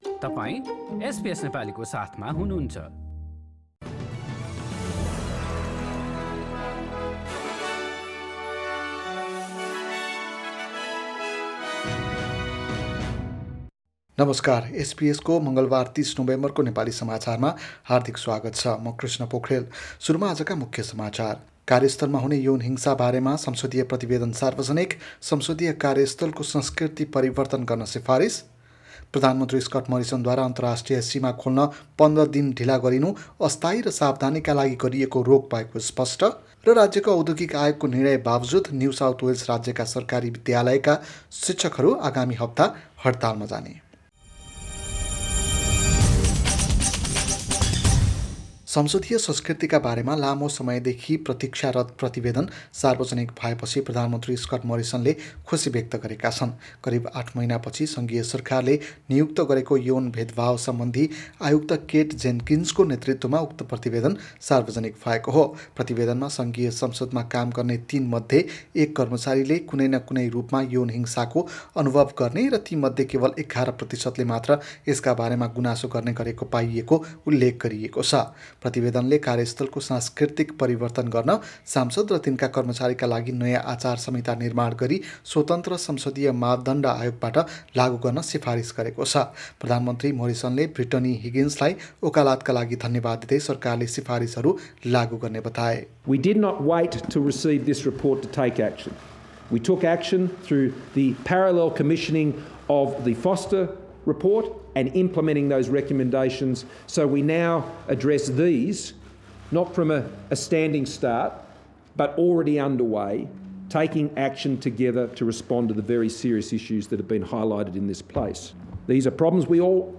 एसबीएस नेपाली अस्ट्रेलिया समाचार: मंगलबार ३० नोभेम्बर २०२१